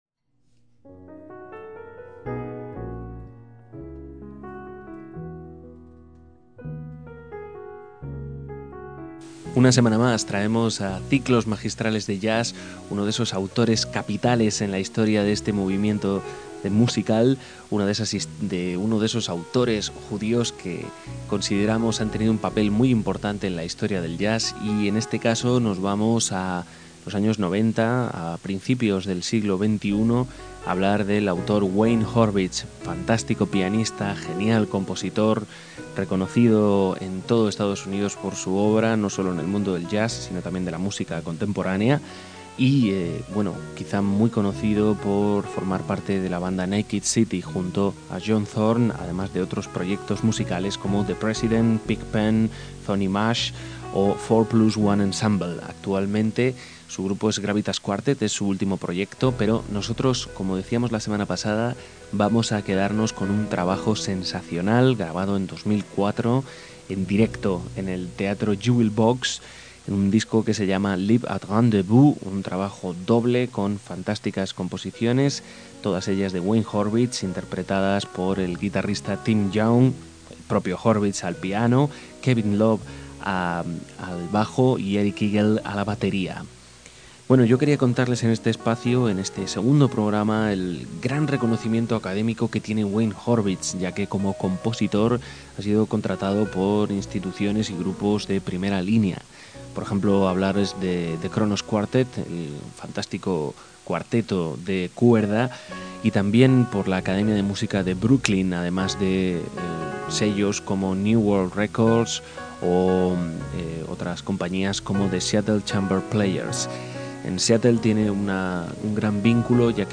arte pianístico
álbum en directo